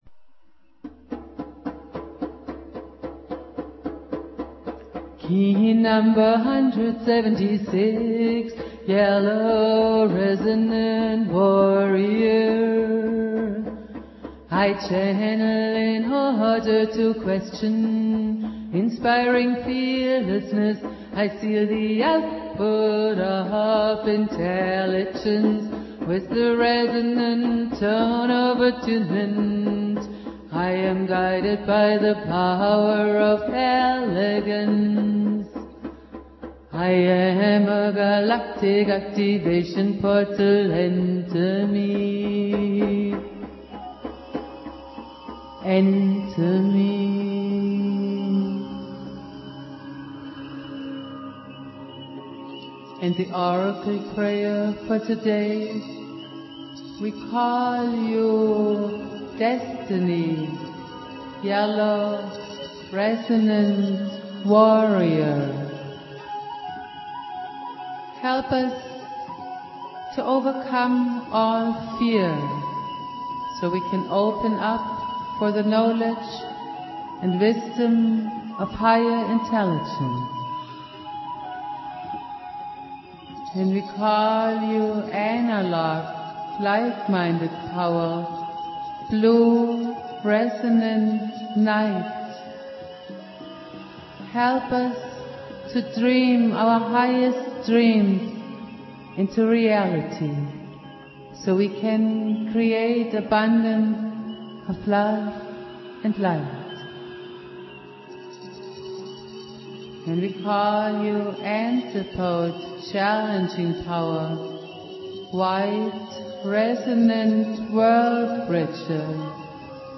Jose Arguelles - Valum Votan playing flute.
Prayer
joswé Arguelles, author of MaYan FacTor - plays a Flute